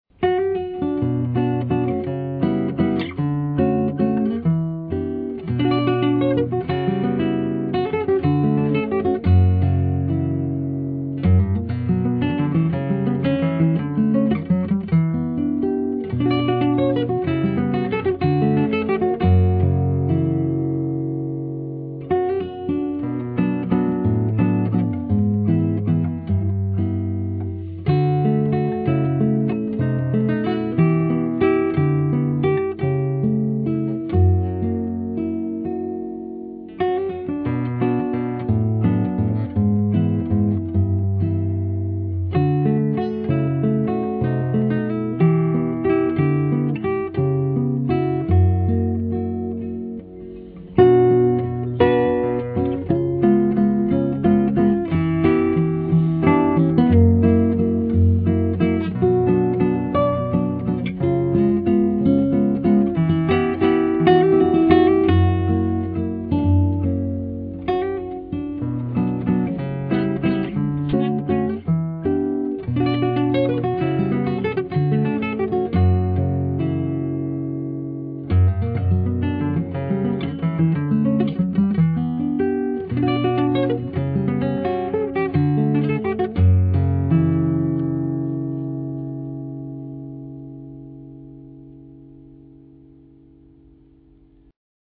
גיטרה
רגוע ושלוו כזה..
וכמה שהיא יפה ורגועה...
זה אמנם רק גיטרה אבל יש פה הכל..